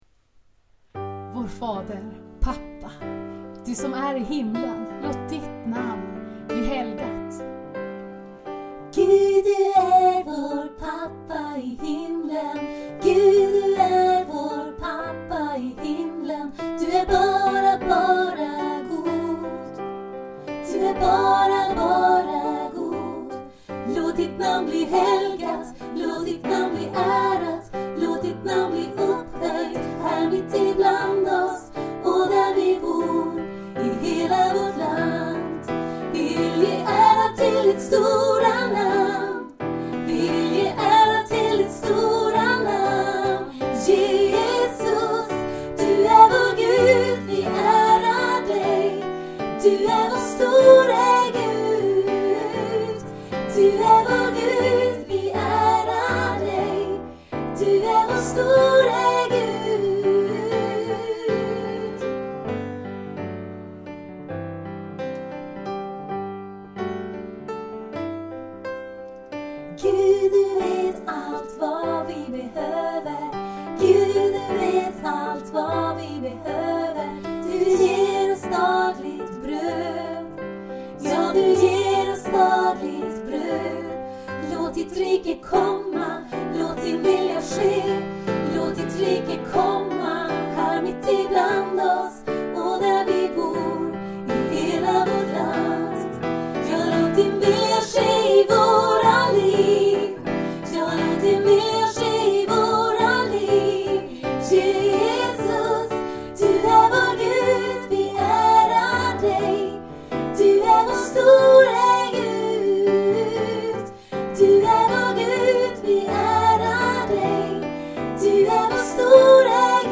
En bönesång där man till en början kan använda bara vers 1 och 2, och senare lägga till alla 4 verser.